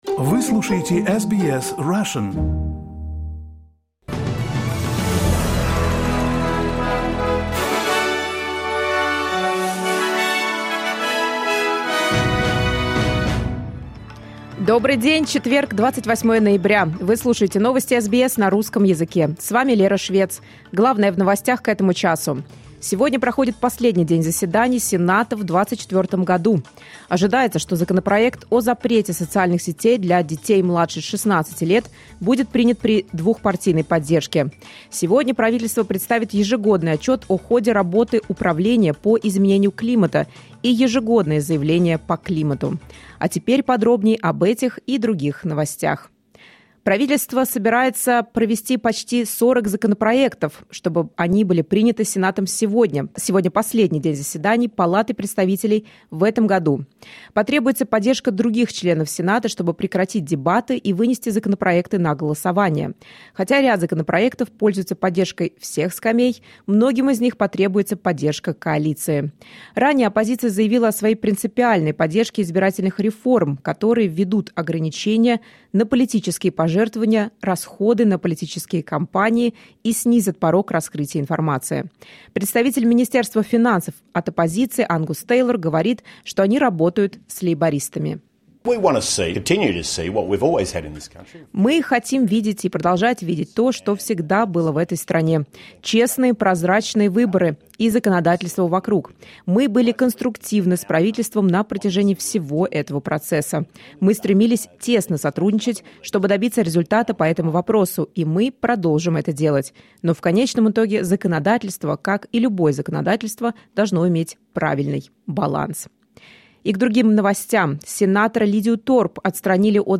Новости SBS на русском языке — 28.11.2024